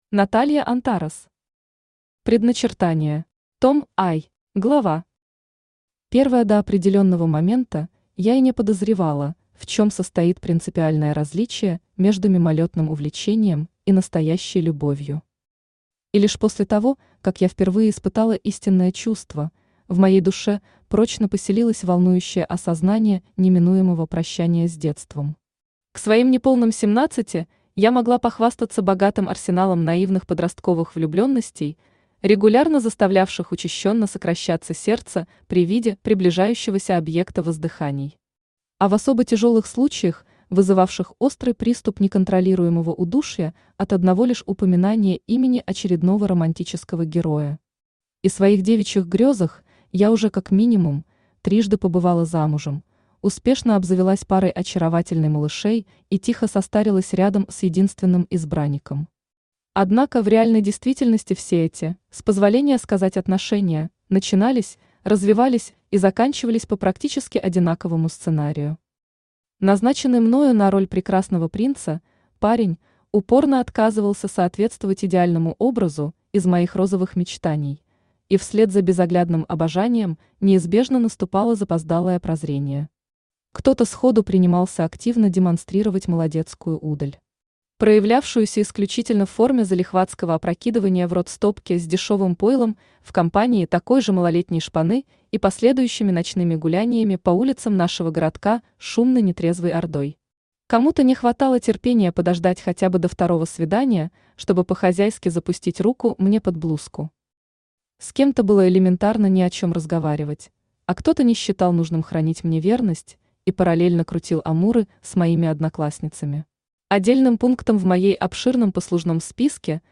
Аудиокнига Предначертание. Том I | Библиотека аудиокниг
Том I Автор Наталья Антарес Читает аудиокнигу Авточтец ЛитРес.